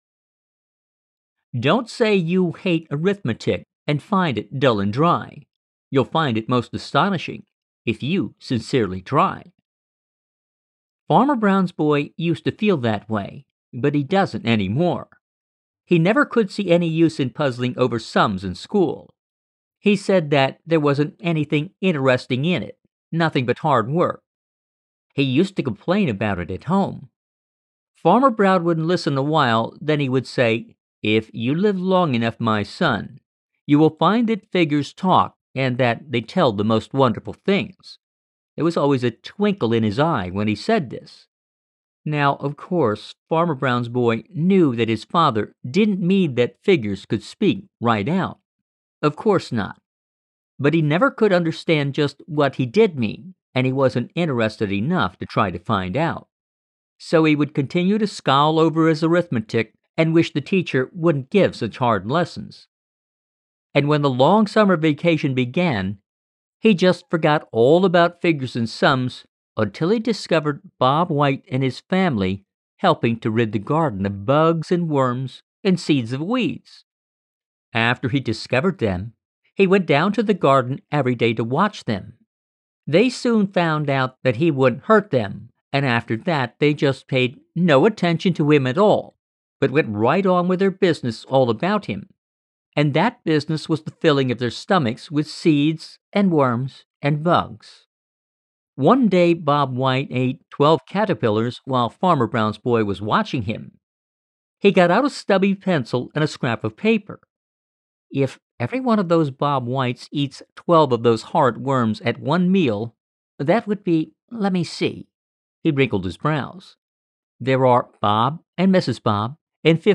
Children's and adult audiobooks